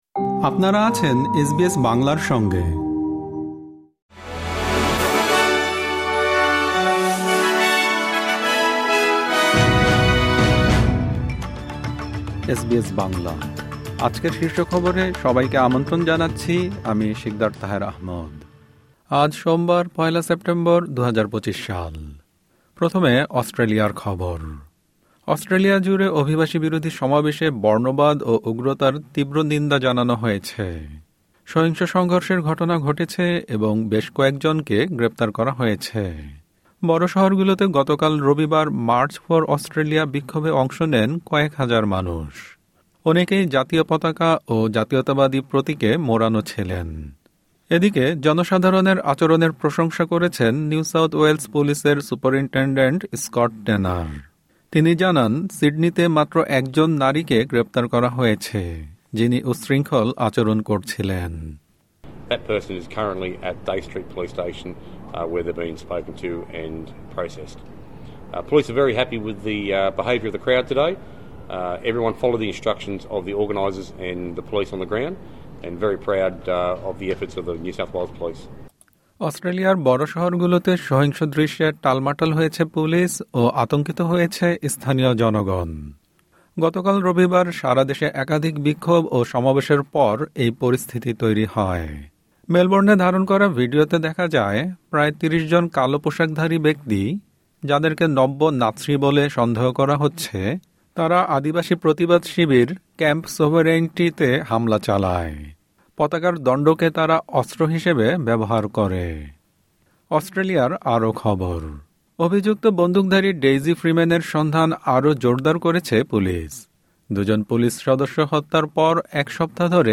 এসবিএস বাংলা শীর্ষ খবর: ১ সেপ্টেম্বর, ২০২৫